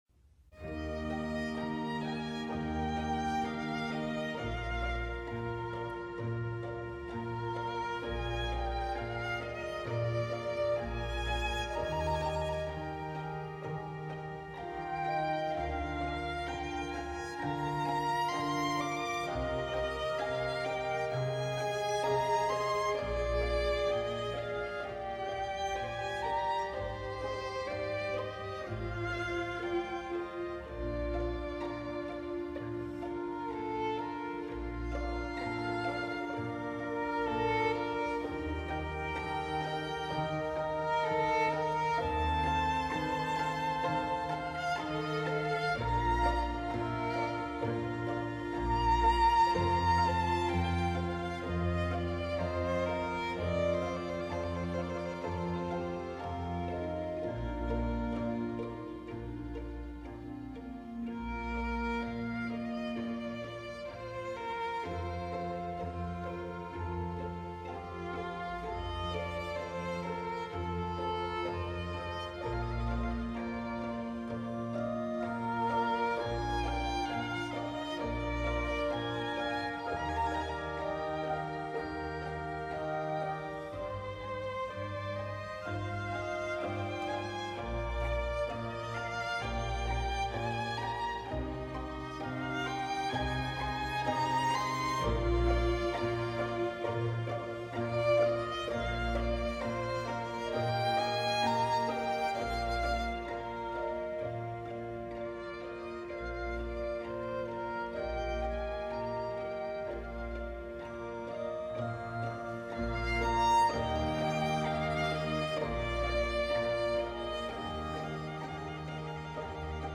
传统的徐缓如歌的中间乐章不仅以小提琴上的拨弦来令人联想到使户外“数百人淋湿”的雨滴声。